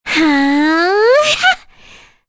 Kirze-Vox_Casting3.wav